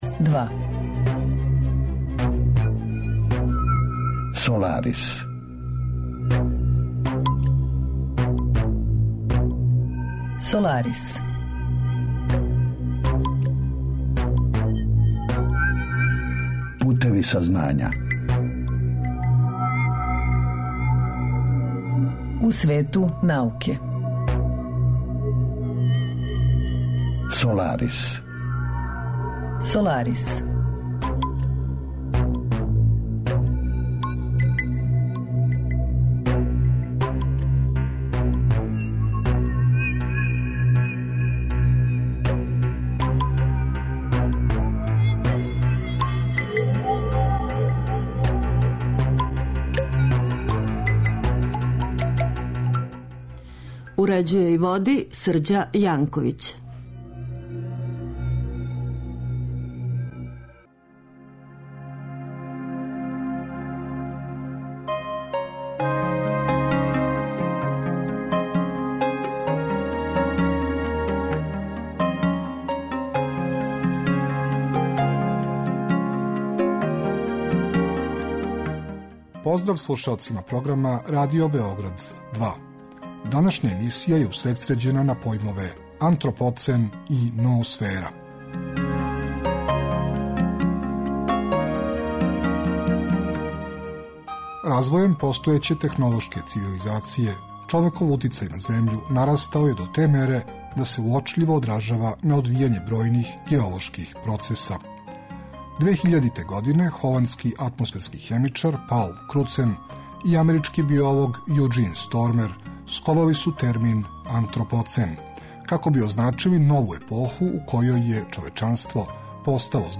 Разговор је први пут емитован 1. априла 2015. године.